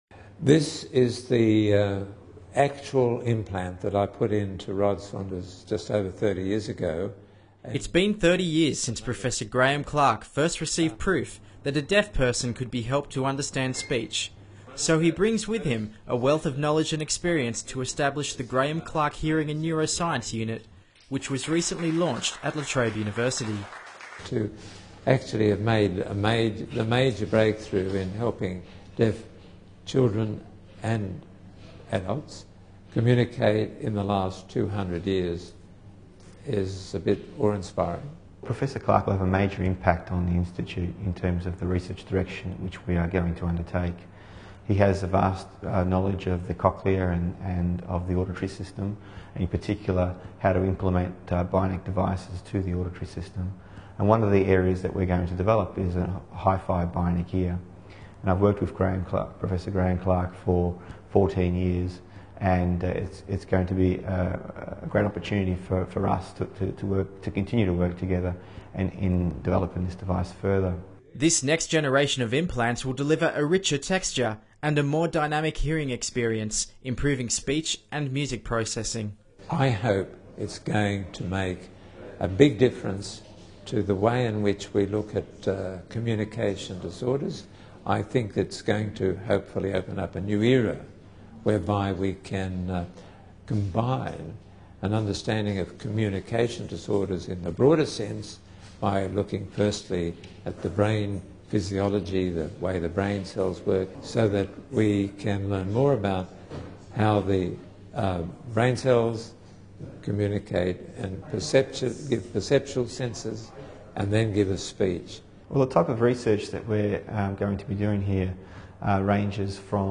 Distinguished Professor Graeme Clark explains in a video news release what his new research in bionic ear technology at La Trobe involves.